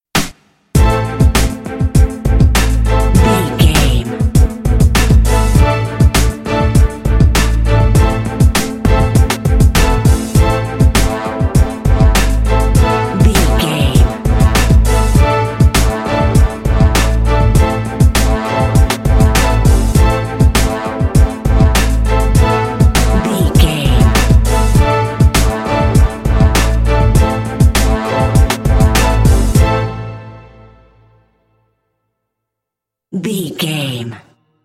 Aeolian/Minor
synthesiser
drum machine
strings
horns
bass guitar